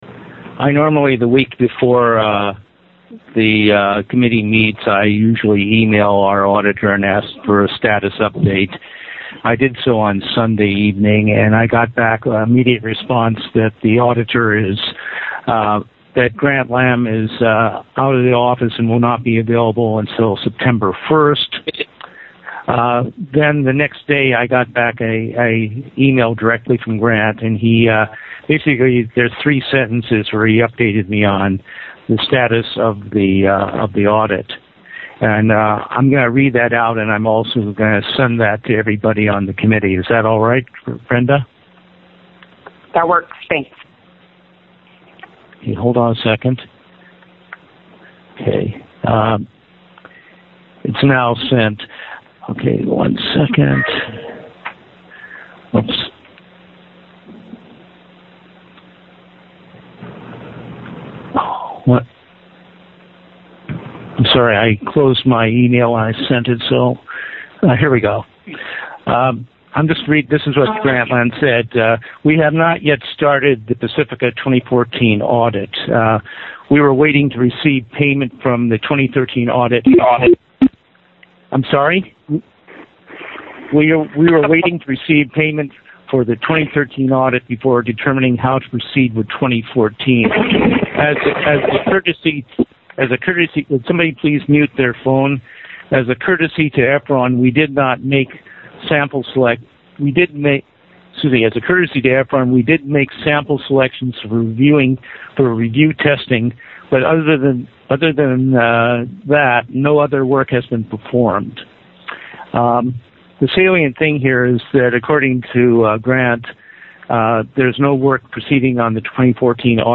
read aloud on the audit committee this evening